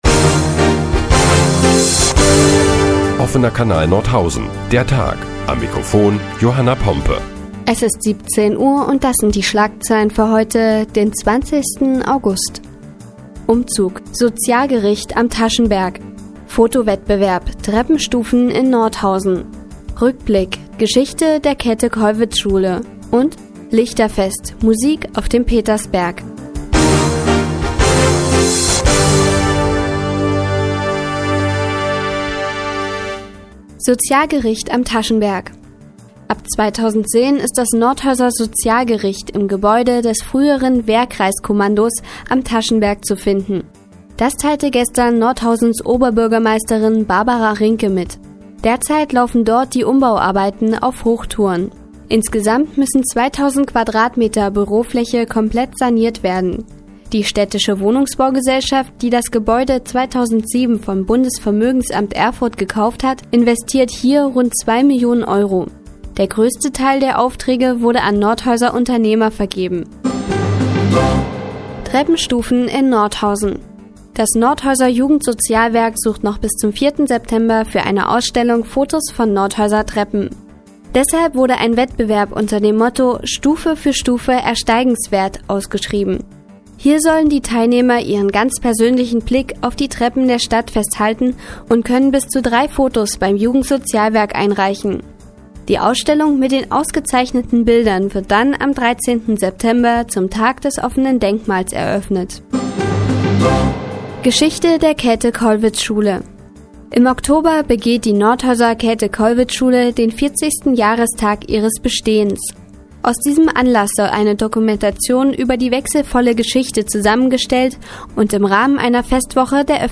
Die tägliche Nachrichtensendung des OKN ist nun auch in der nnz zu hören. Heute geht es unter anderem um einen Foto-Wettbewerb und das Lichterfest in Nordhausen.